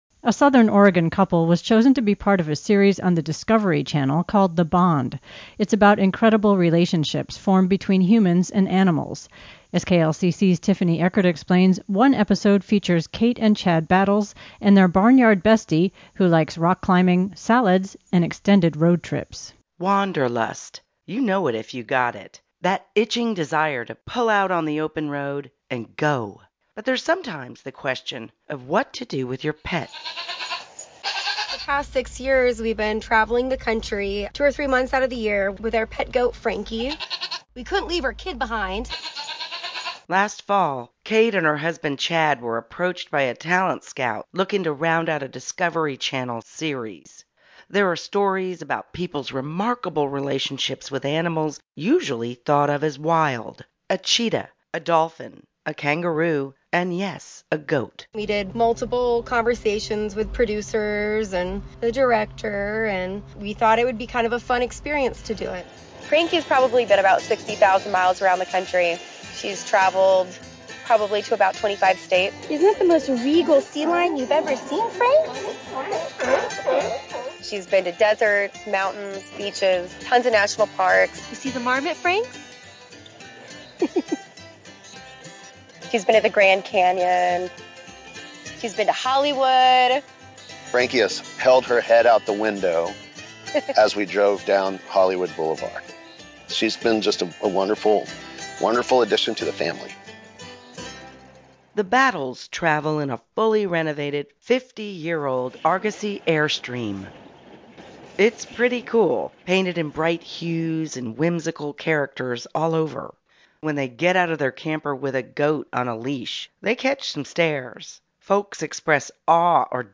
web-frankiethegoat.mp3